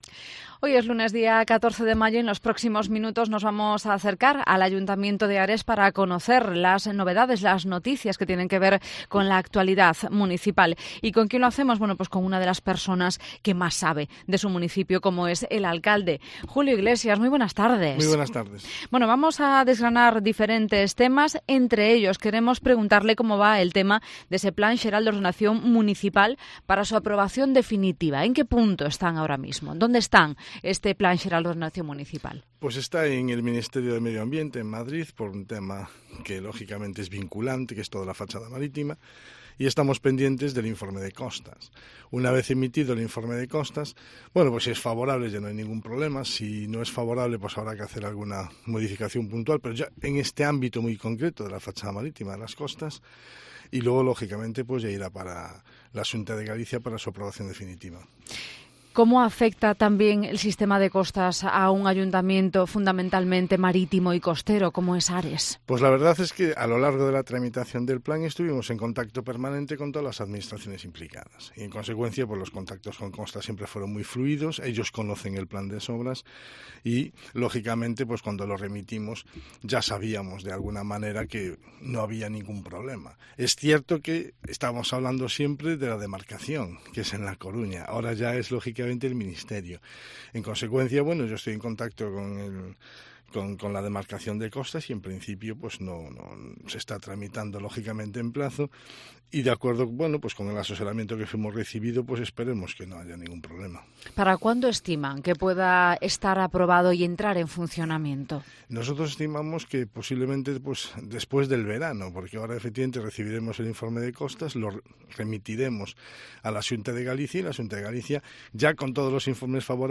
Entrevista al alcalde de Ares, en Cope Ferrol.